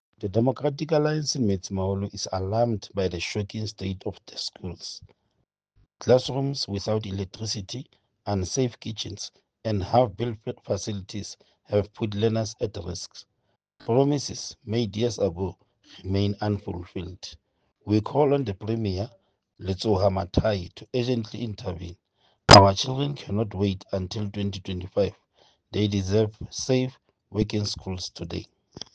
Sesotho soundbites by Cllr Stone Makhema.